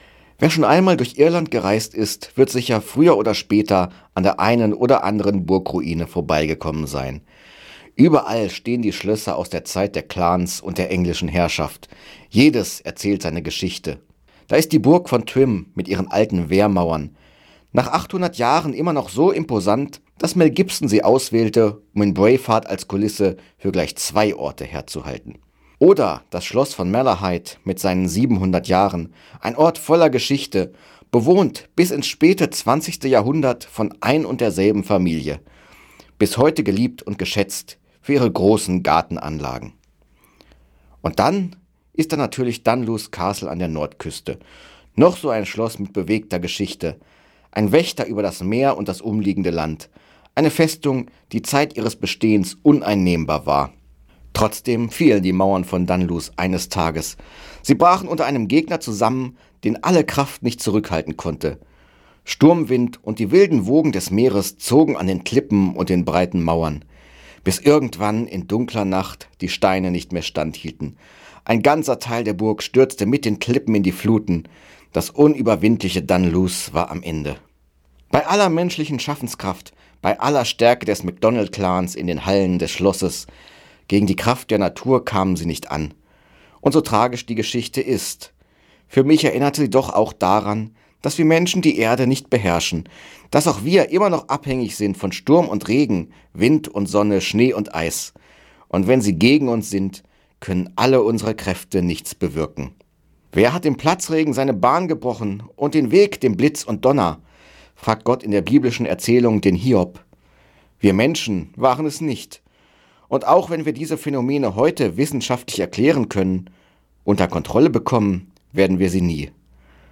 Radioandacht vom 12. Februar